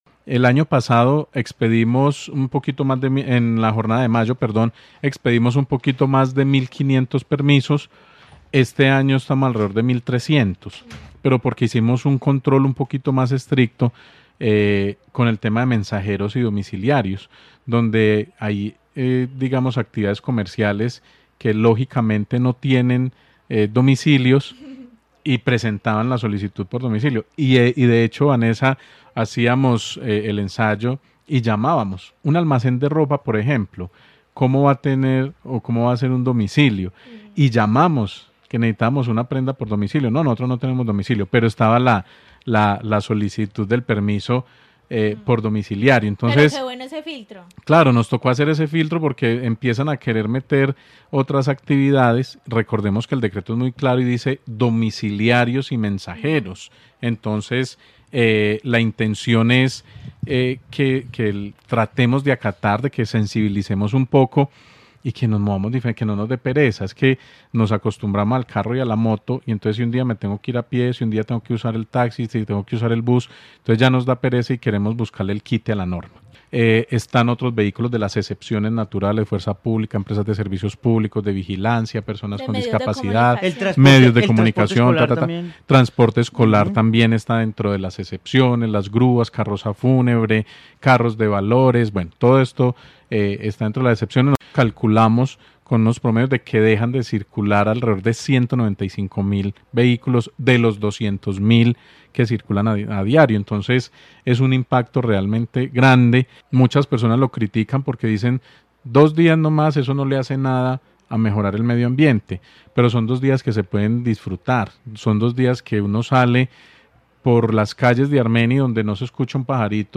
Daniel Jaime Castaño secretario de Tránsito de Armenia
En diálogo con Caracol Radio Armenia, el secretario de Tránsito Daniel Jaime Castaño mencionó que calculan aproximadamente 195 mil vehículos dejen de circular por la jornada de hoy de los 200 mil que circulan a diario por las vías de la ciudad.